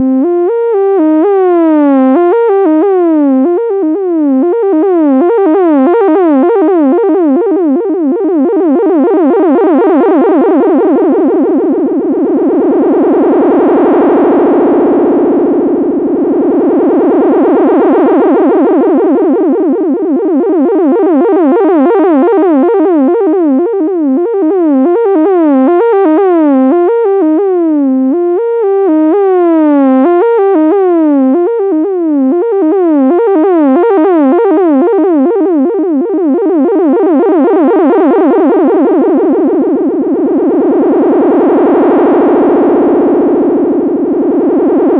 The featured gesture here is controlling the pitch of an oscillator with a small bit of glissando. Every time the gesture loops back, the speed changes: eighths (2), triplets (3), sixteenths (4), quintuplets (5), etc. until it reaches a really high value and then it goes backwards and slows down.